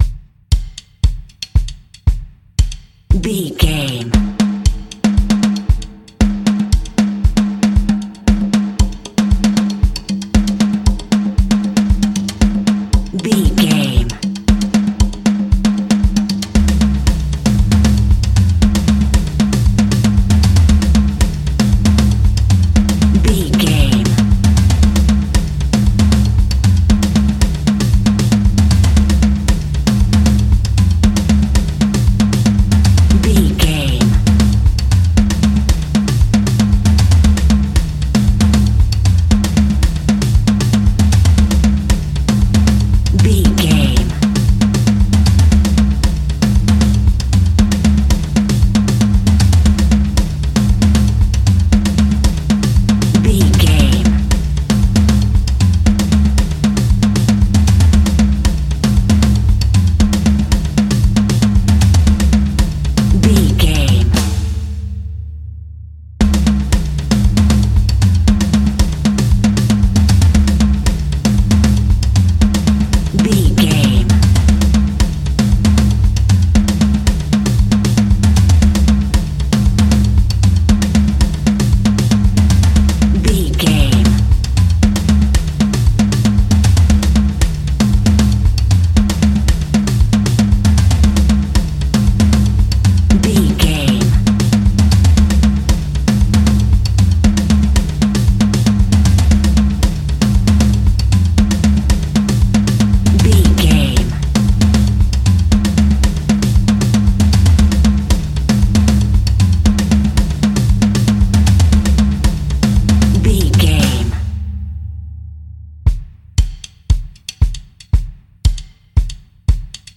Aeolian/Minor
latin
salsa
uptempo
percussion
brass
saxophone
trumpet
fender rhodes
clavinet